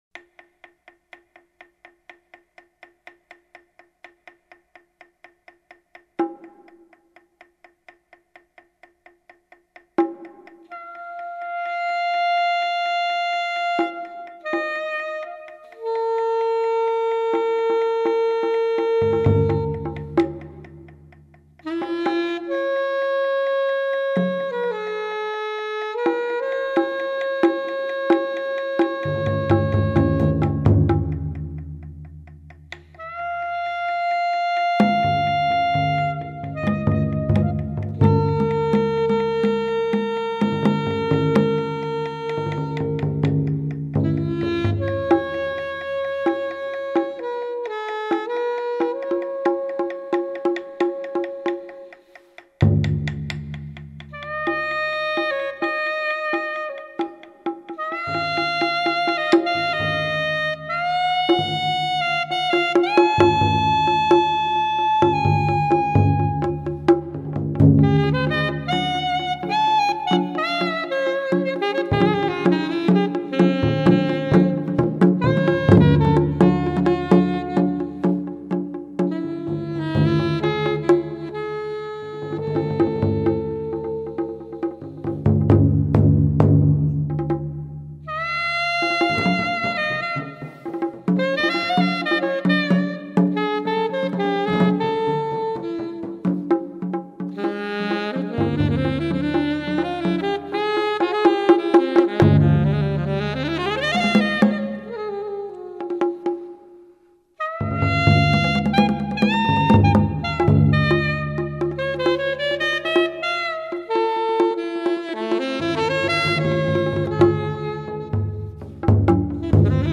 taiko, percussion, alto saxophone, fue, Mexican clay flute